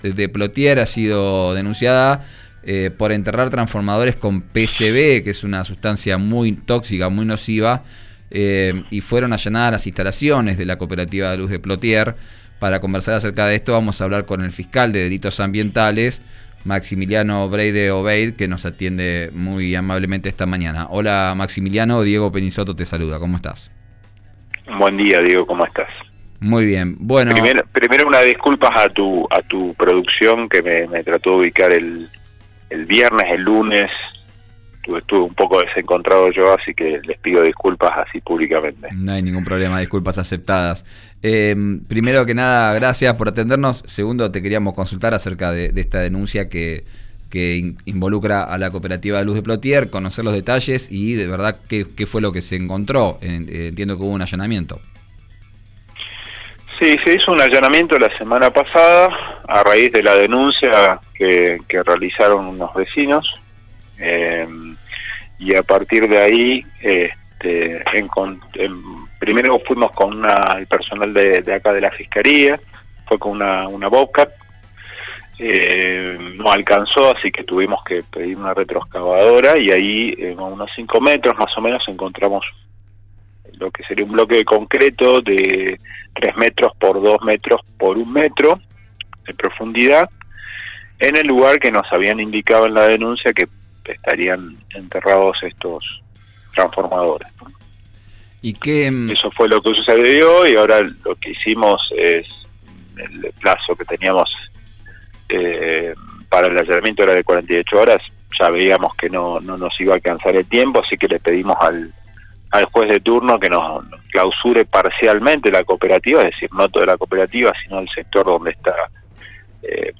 Escuchá al fiscal Maximiliano Breid Obeid en RÍO NEGRO RADIO: